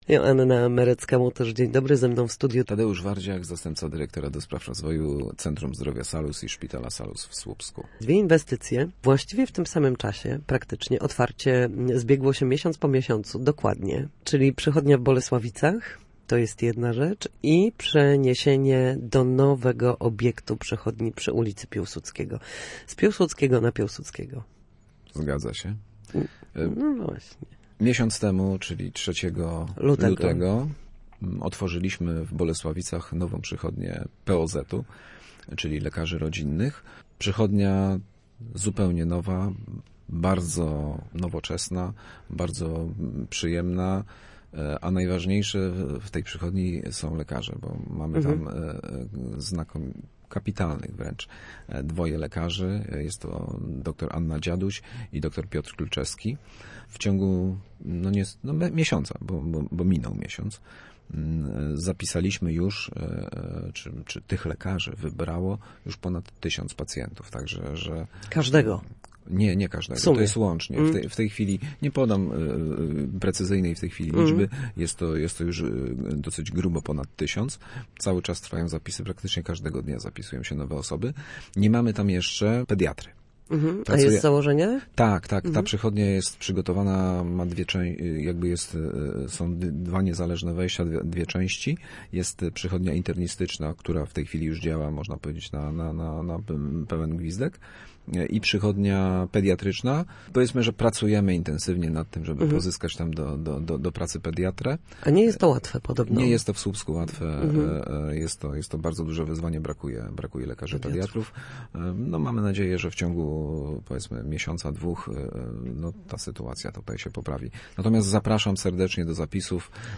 Gościem Studia Słupsk